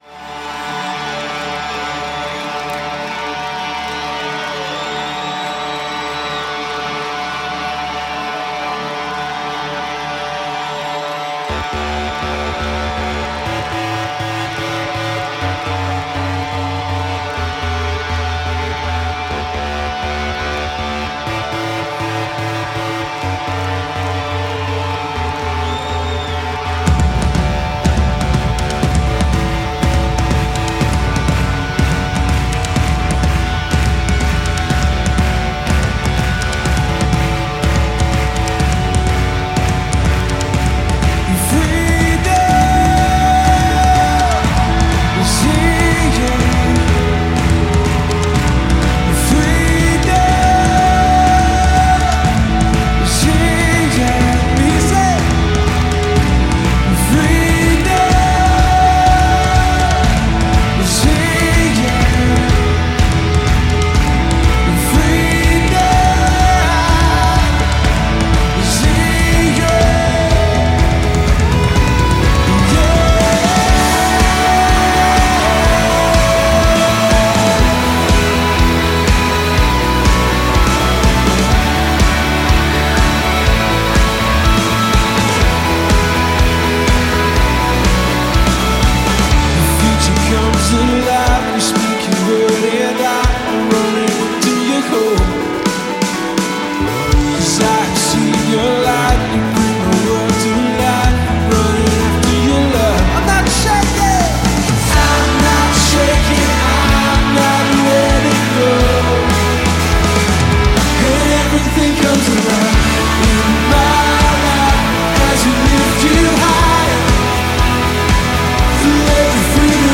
373 просмотра 204 прослушивания 9 скачиваний BPM: 123